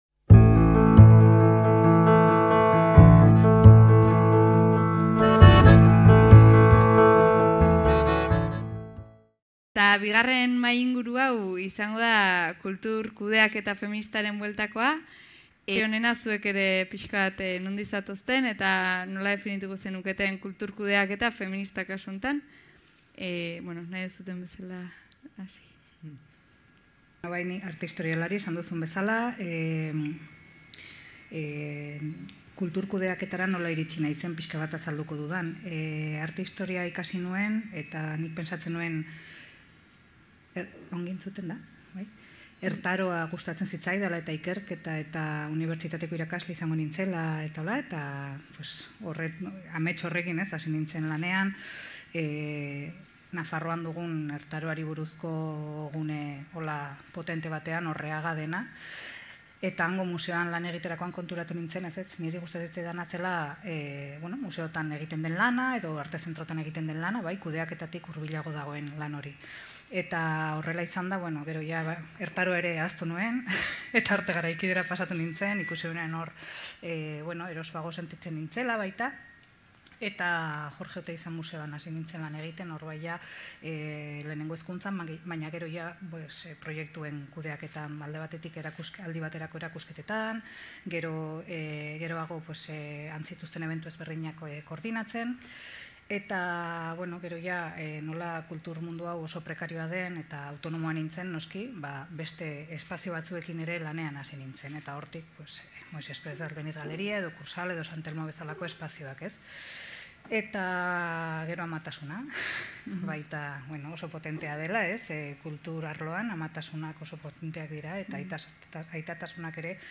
Eusko Ikaskuntzako Solasaldiak Kultur kudeaketa feminista Zeintzuk izango lirateke kultura-kudeaketako praktika onak ikuspegi feministatik? Irailean egin zen Bilboko Sinposioaren barruan, Kultur kudeaketa ikuspegi feministatik gaiari buruzko mahai-inguru bat egin zen, eta Solasaldiak sailak laburpen gisa berreskuratu du.